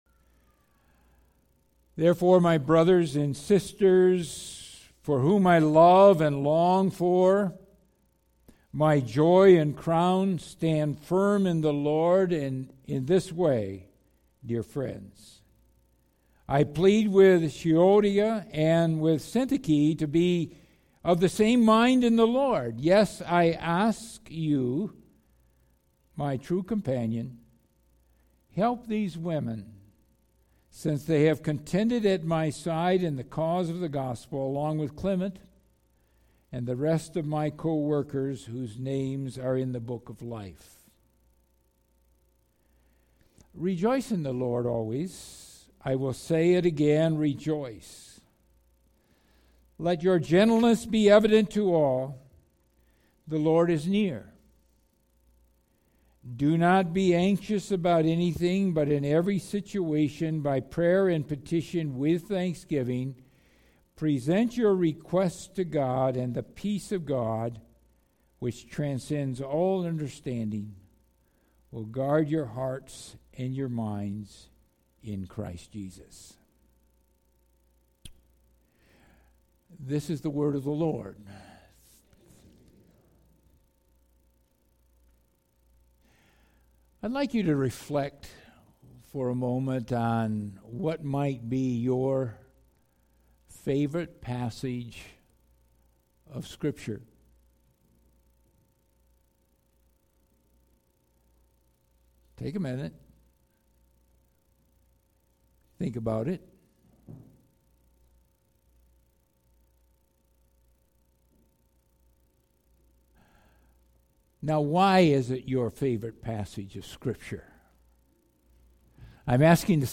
Philippians 4:4-9 Service Type: Sunday AM Bible Text